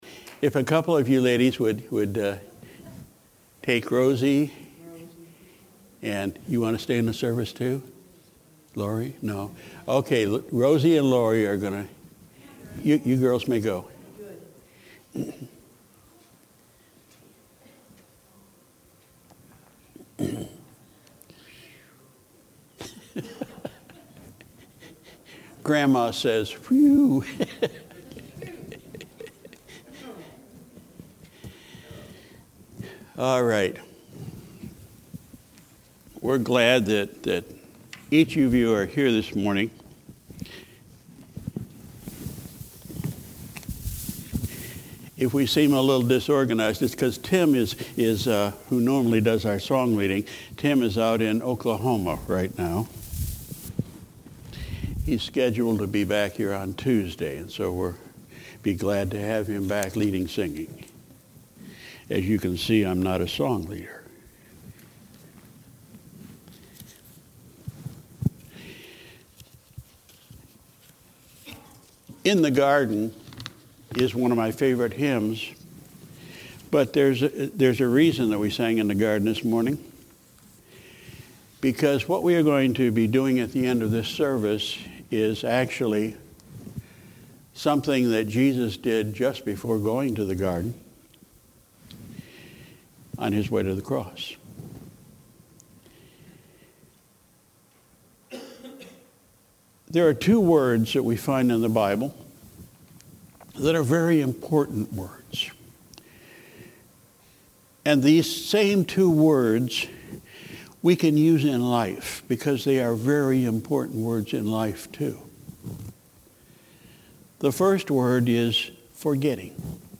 January 7, 2018 Morning Service – Forgetting and Remembering